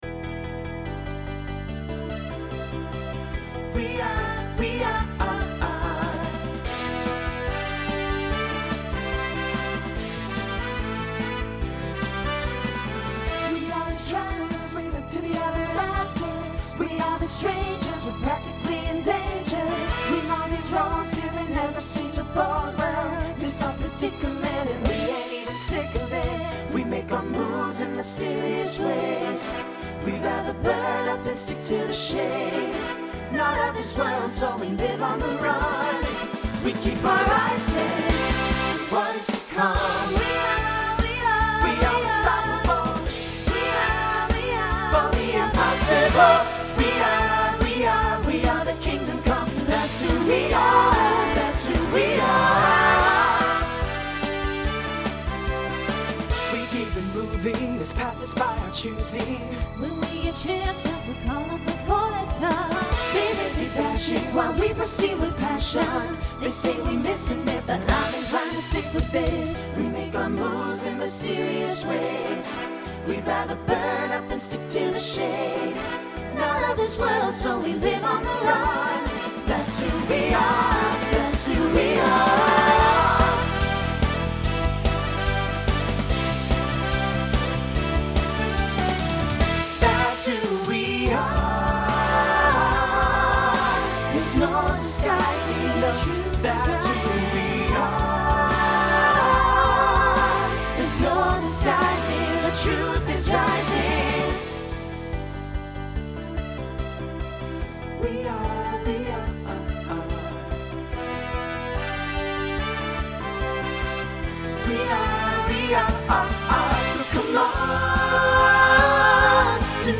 New SSA voicing for 2023.